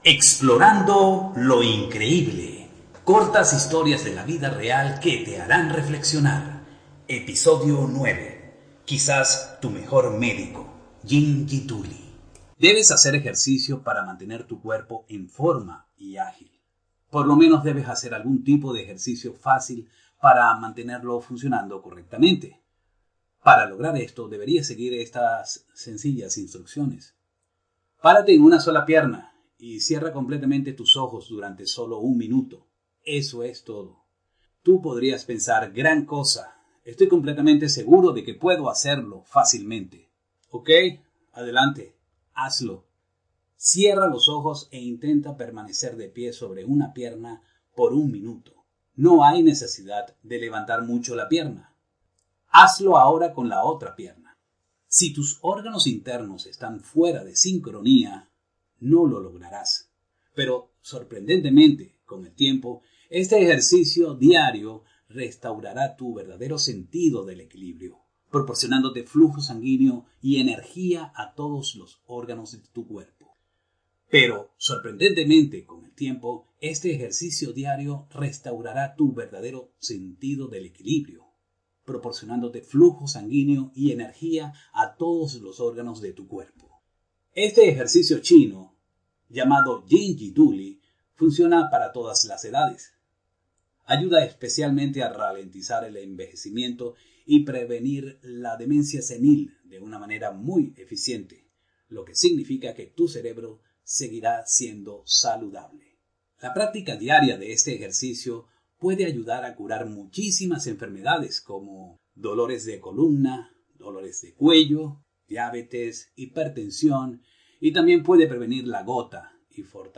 kastilisch
Sprechprobe: Sonstiges (Muttersprache):
I offer a fresh young adult voice in Spanish. The voice reveals a pleasant and attractive character that projects security and confidence to customers